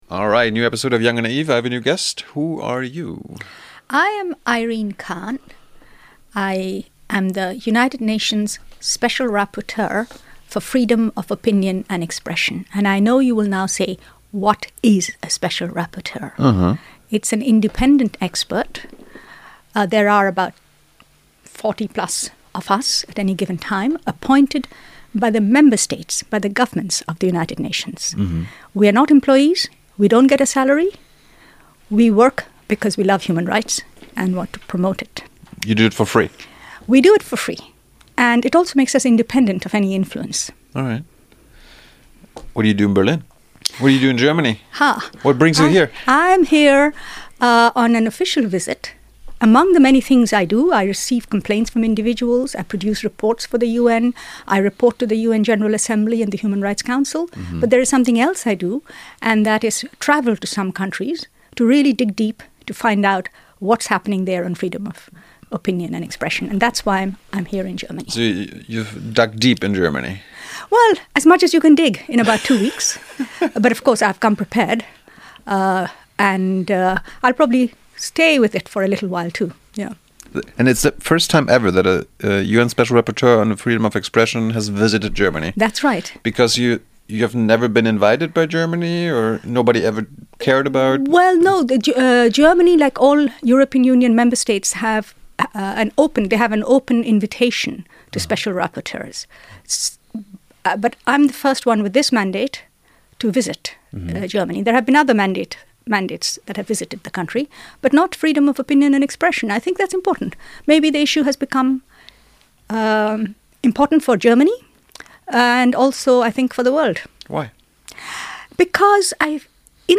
Zu Gast im Studio: Irene Khan.